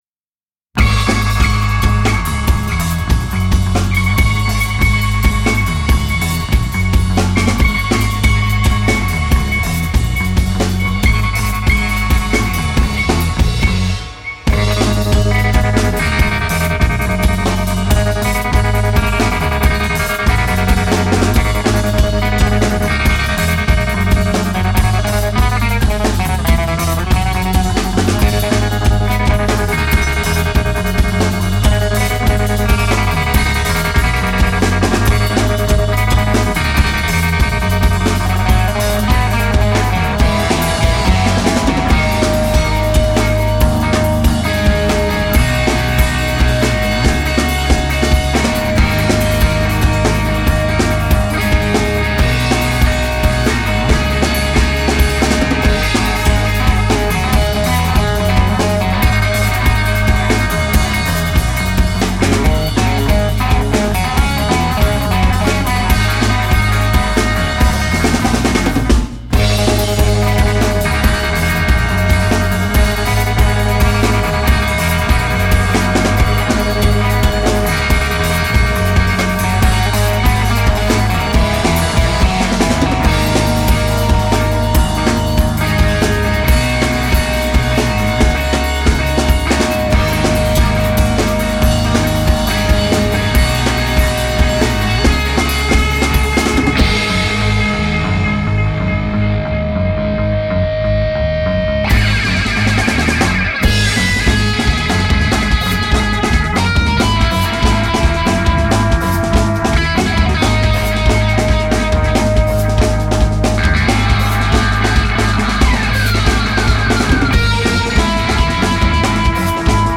Spain post-core experiemental and instrumental band